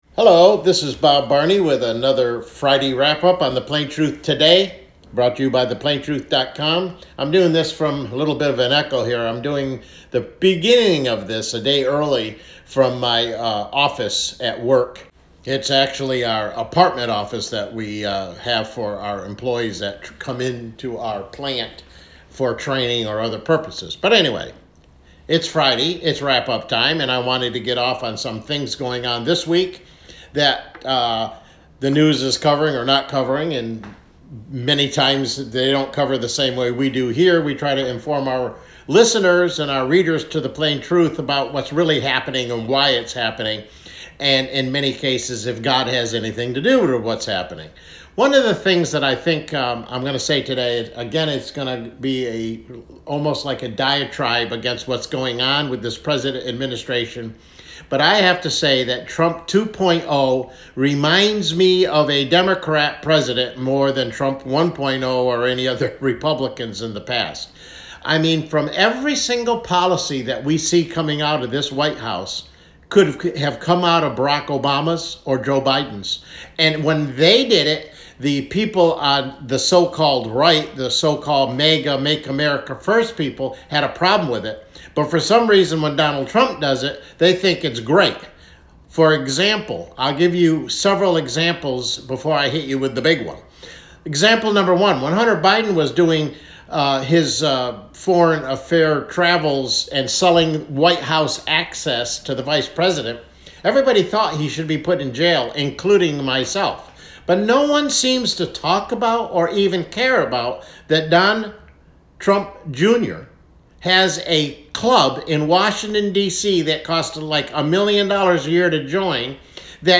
I’m doing this from a little bit of an echo here.